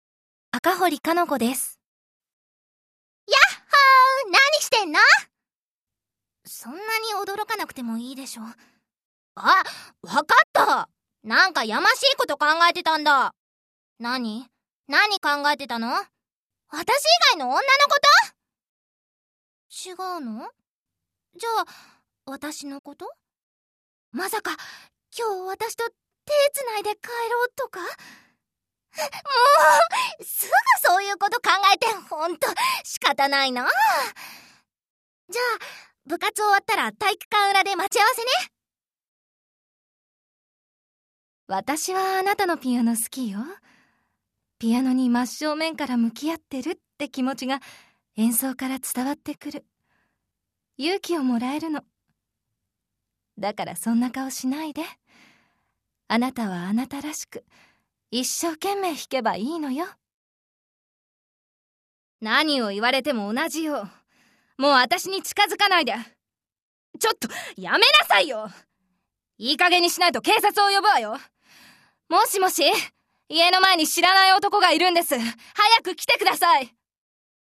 ボイスサンプル
女子高生 女子高生(ダウナー系) 幼い女学生 ツンデレ風 20代_穏やかで優しい系 先輩OL 優しいお母さん 真面目な母親 30代以降_妖艶系 男の子